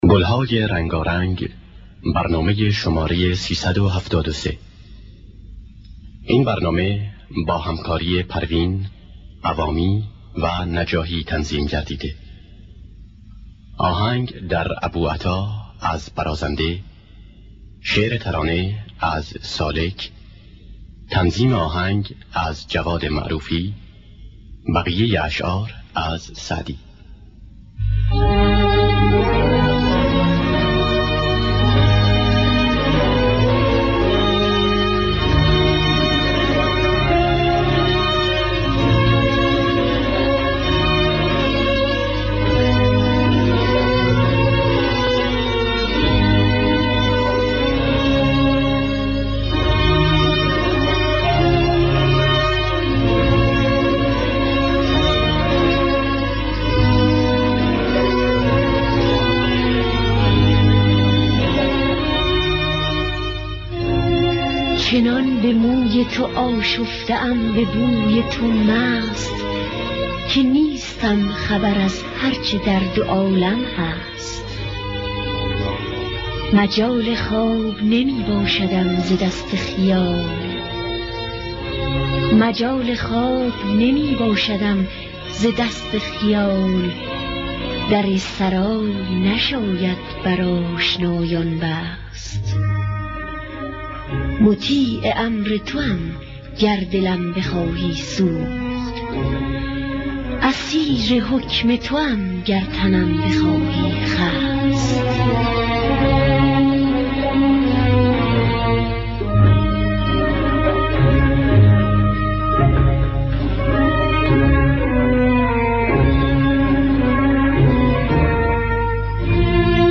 خوانندگان: پروین حسین قوامی